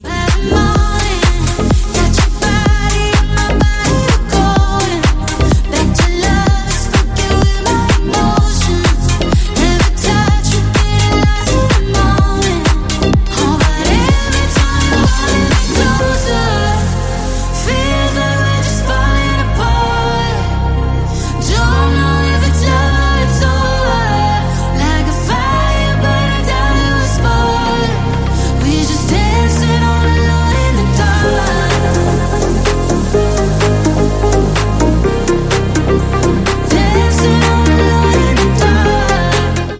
клубные , зажигательные , house